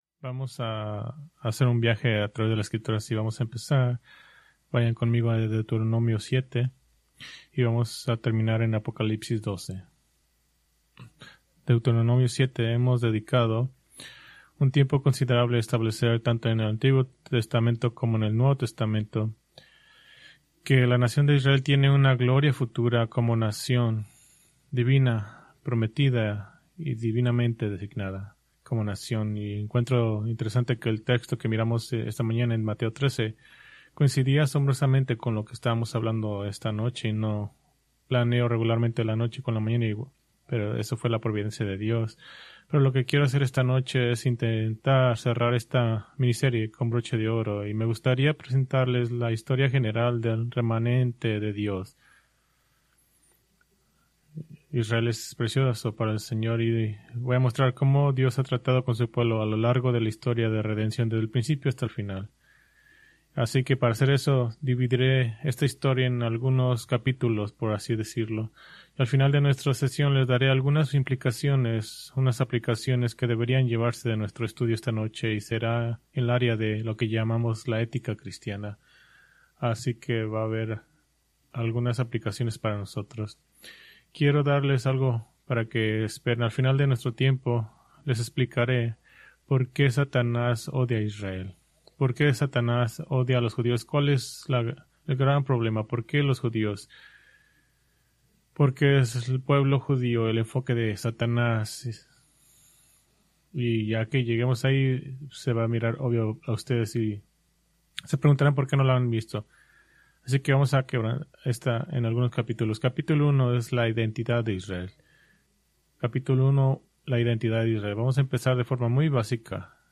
Preached February 1, 2026 from Escrituras seleccionadas